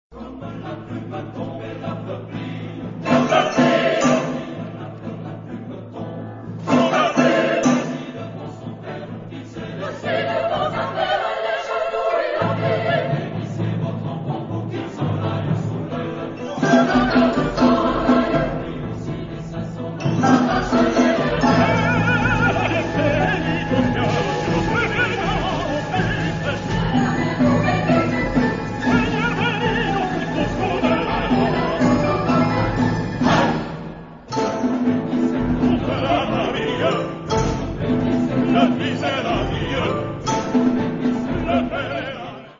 Genre-Style-Form: Cantata ; Secular ; Contemporary
Type of Choir: SSAATB  (6 mixed voices )
Soloist(s): STB  (3 soloist(s))
Instrumentation: Orchestra
Tonality: B flat major